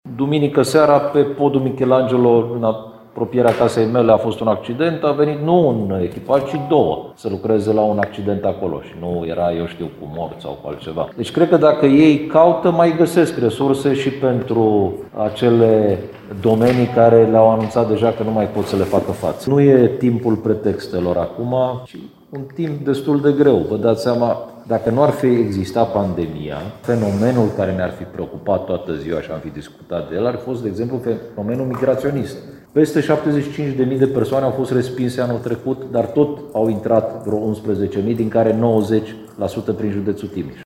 Subprefectul de Timiș, Ovidiu Drăgănescu, avertizează, însă că, nu este momentul pentru pretexte.